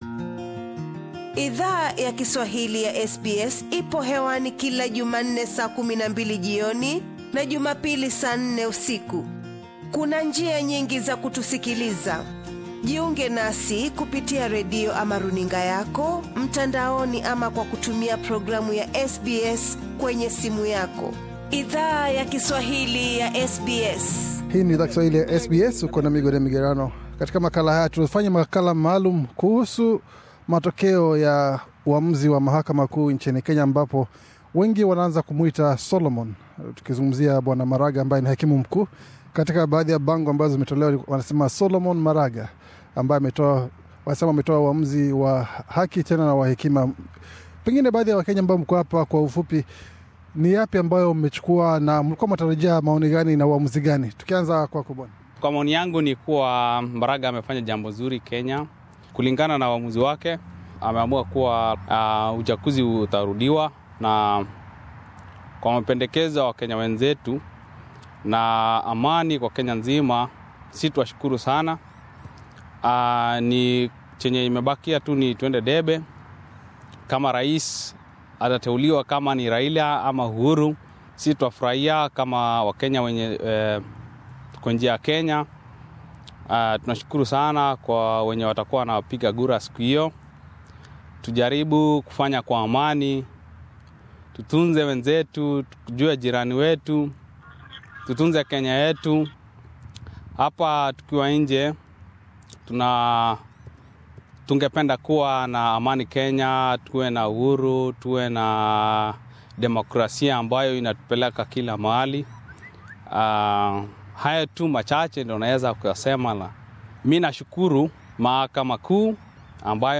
Punde baada ya Mahakama Kuu ya Kenya kuamua kufuta matokeo ya uchaguzi wa urais naku amuru uchaguzi mpya wa urais nchini Kenya, SBS Swahili ilizungumza na baadhi ya wakenya wanao ishi Australia, ambao walifunguka kuhusu uamuzi huo.